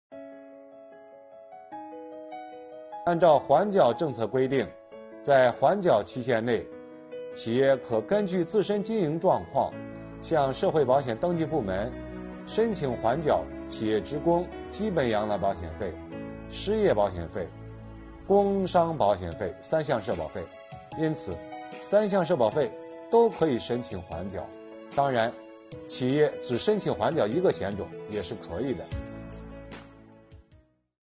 近日，国家税务总局推出新一批“税务讲堂”系列课程，为纳税人缴费人集中解读实施新的组合式税费支持政策。本期课程由国家税务总局社会保险费司副司长王发运担任主讲人，对公众关注的特困行业阶段性缓缴企业社保费政策问题进行讲解。